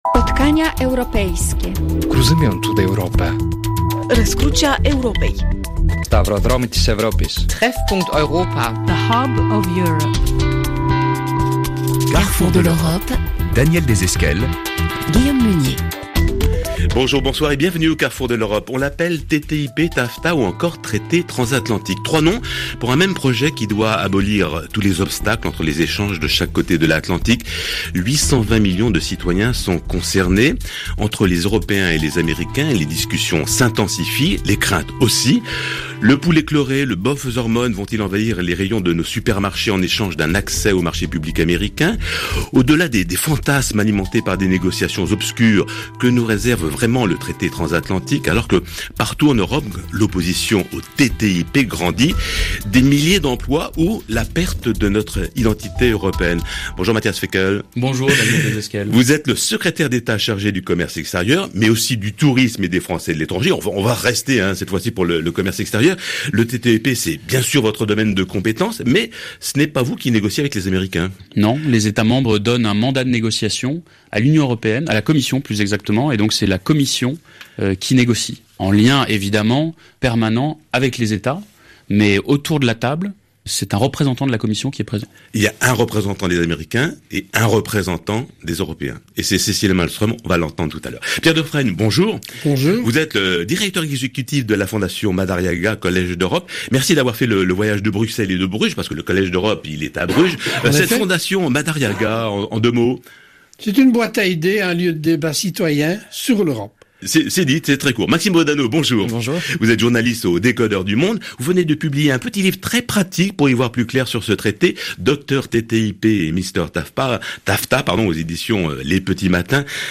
La page TTIP du MAE : Cliquer ici Avec : - Matthias Fekl, secrétaire d'Etat chargé du Commerce extérieur et de la promotion du Tourisme et des Français de l'étranger.